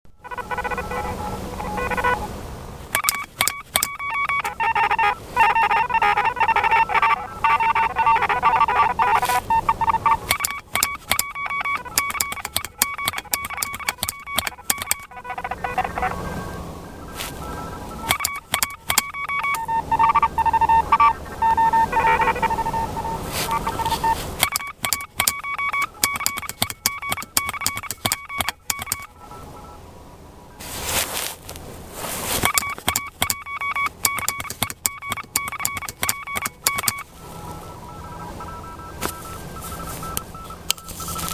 Я отметился в CQ WW Contest из лесов- полей, QRP/p. Естественно никакого потрясающего результата, его и быть не могло!